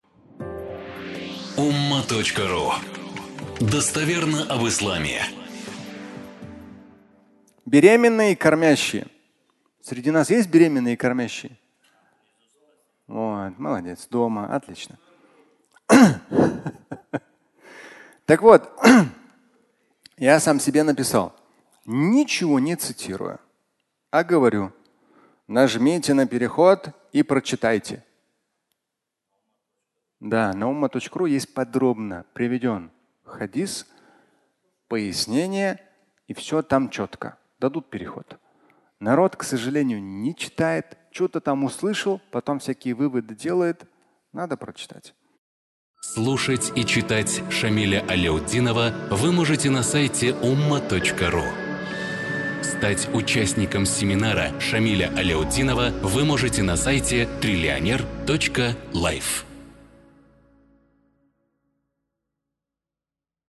Беременные и кормящие (аудиолекция)